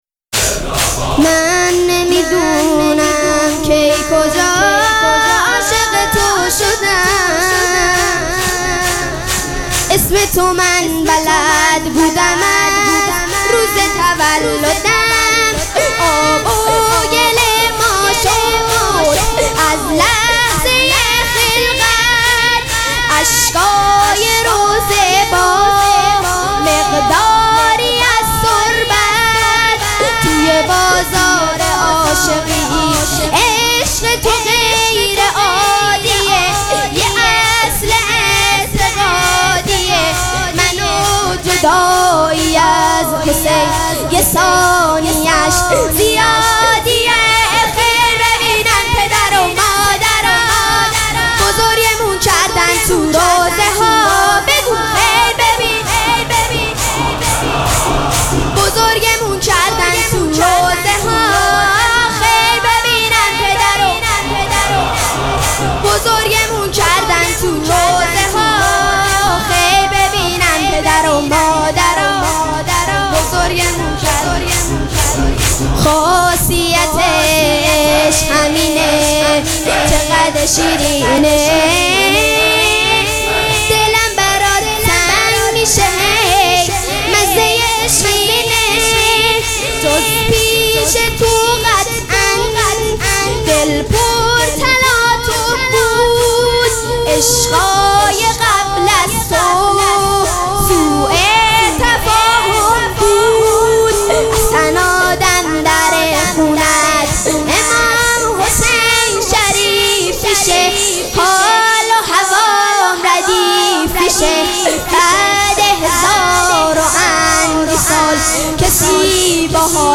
مراسم عزاداری شب دهم محرم الحرام ۱۴۴۷
هیئت ریحانه الحسین سلام الله علیها
شور